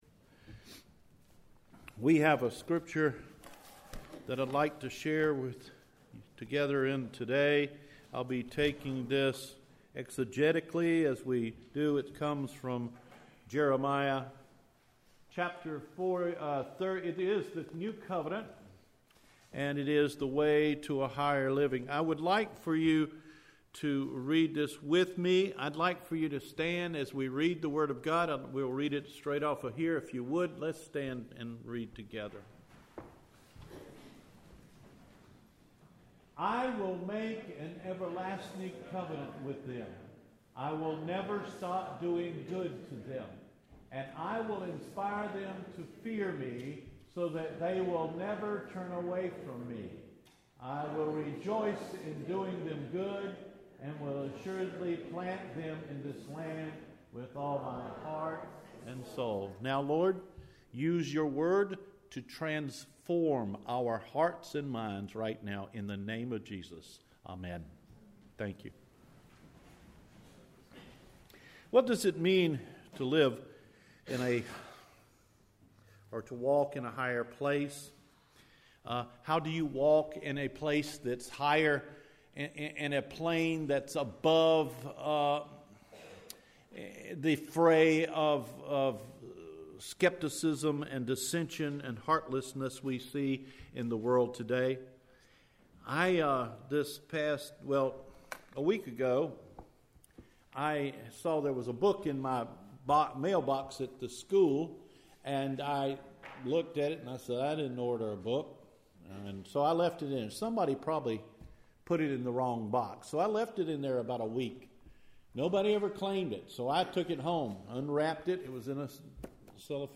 Walking in Higher Places – February 25, 2018 Sermon